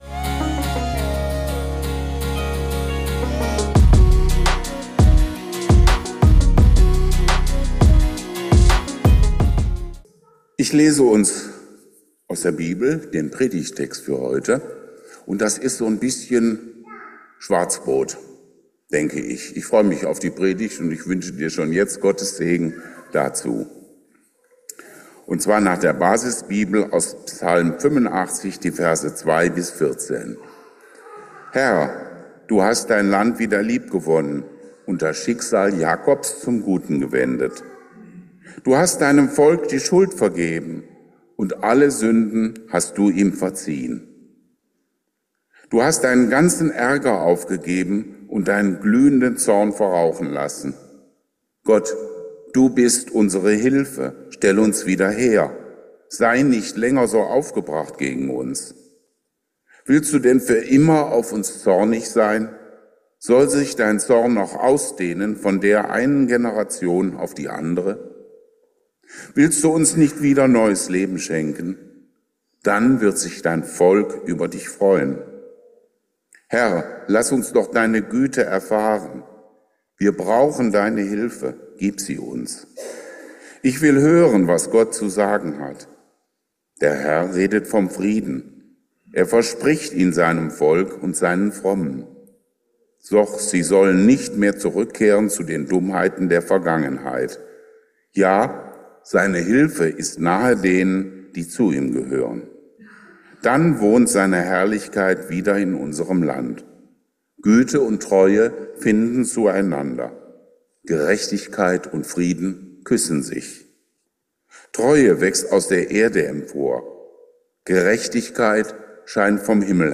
Keine Spaß-Predigt zu Weihnachten